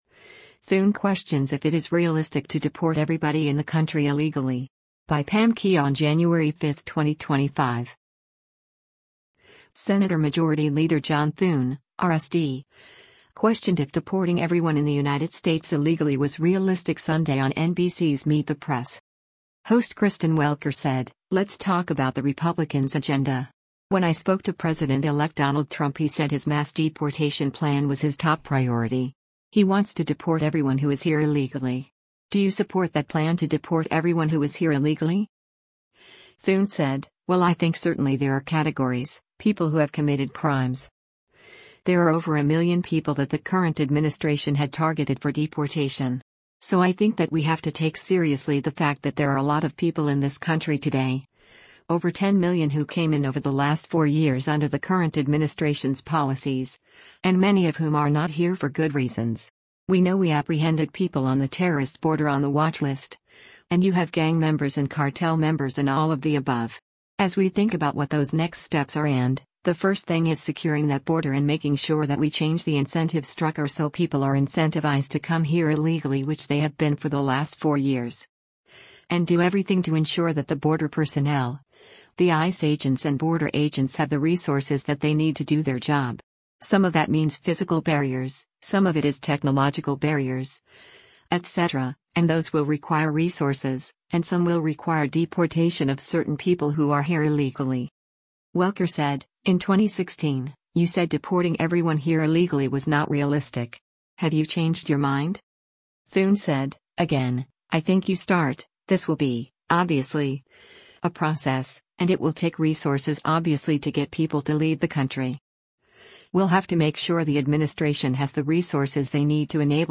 Senator Majority Leader John Thune (R-SD) questioned if deporting everyone in the United States illegally was “realistic” Sunday on NBC’s “Meet the Press.”